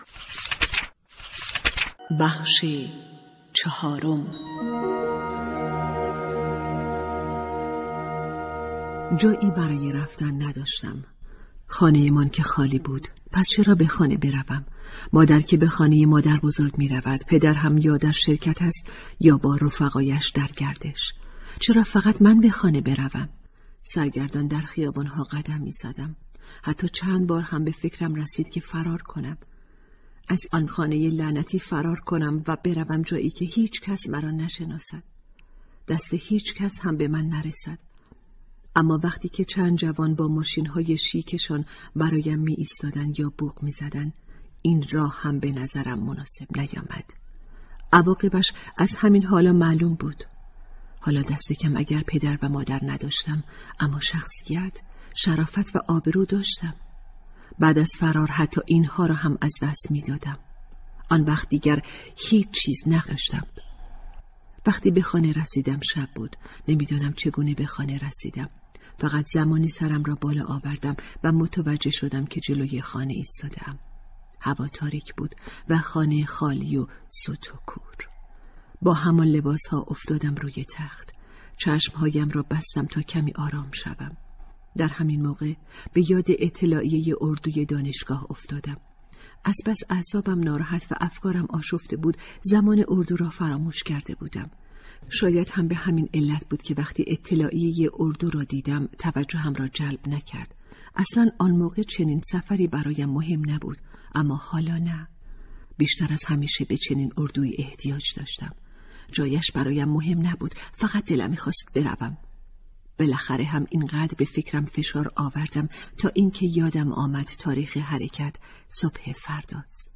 کتاب صوتی | دختران آفتاب (04)